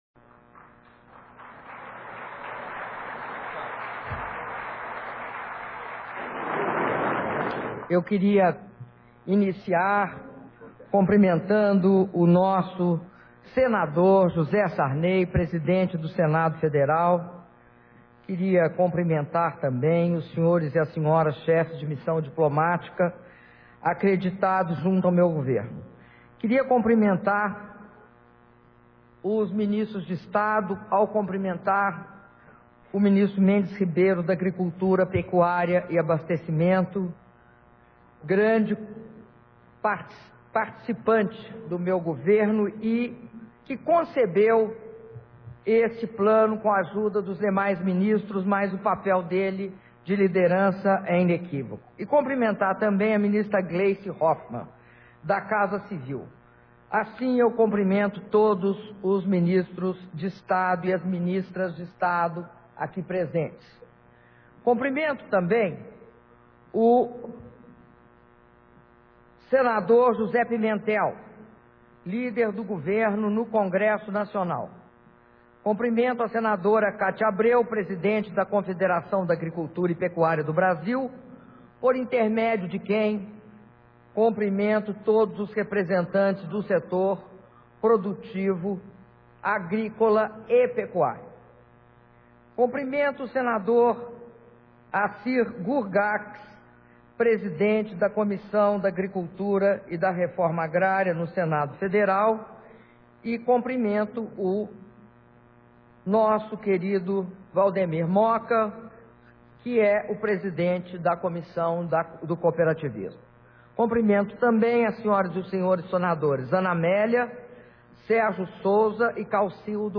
Discurso da Presidenta da República, Dilma Rousseff, na cerimônia de lançamento do Plano Agrícola e Pecuário 2012/2013
Palácio do Planalto, 28 de junho de 2012